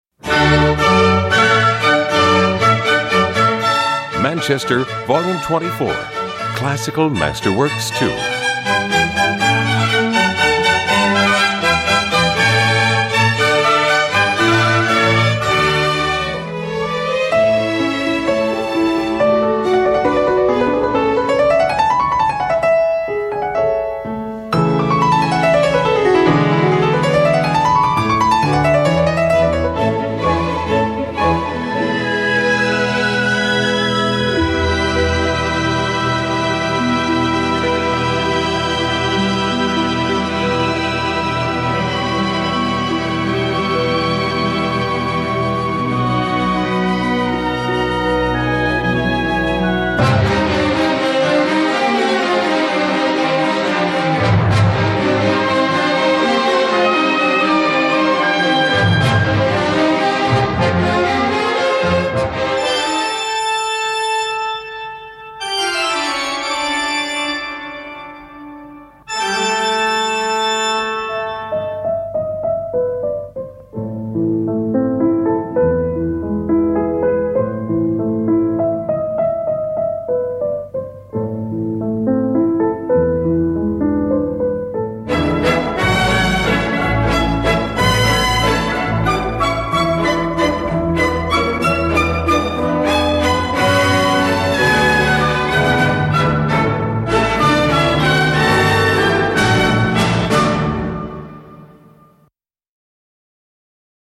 Premium Quality "Classical" Production Music:
• Recorded in Europe featuring virtuosos instrumentalists
• From solo piano to chamber music to full orchestra